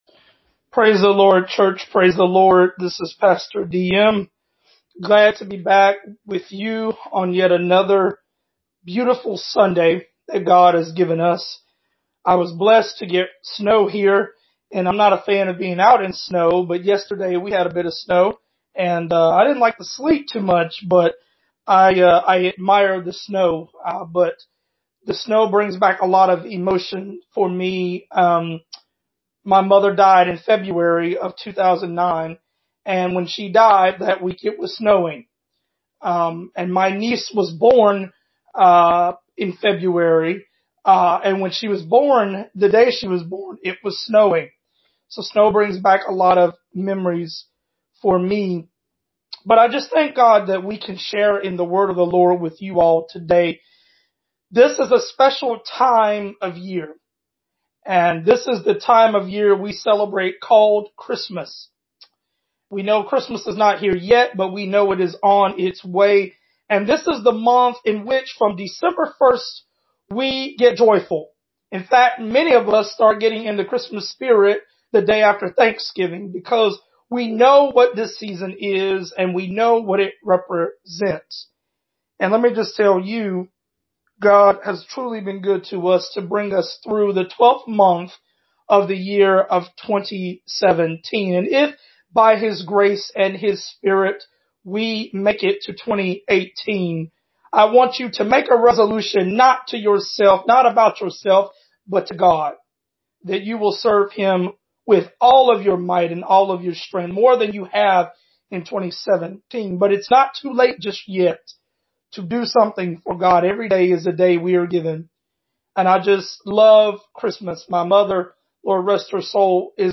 Sermon: The Virgin Birth: Answering Questions About the Inconceivable Scripture: Luke 1:26-38